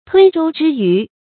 吞舟之魚 注音： ㄊㄨㄣ ㄓㄡ ㄓㄧ ㄧㄩˊ 讀音讀法： 意思解釋： 能吞舟的大魚。